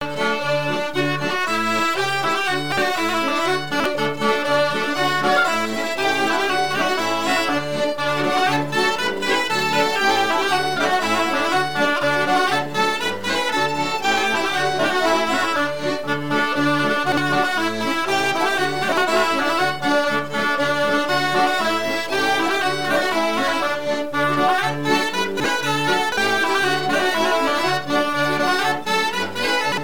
danse : branle : courante, maraîchine
Pièce musicale éditée